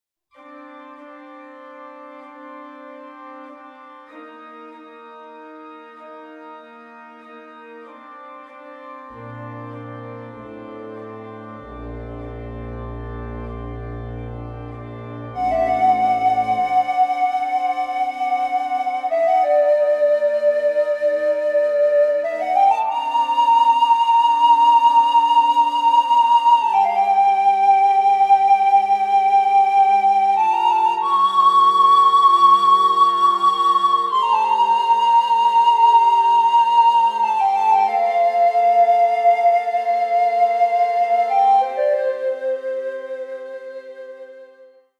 recorders, organ
(Audio generated by Sibelius/NotePerformer)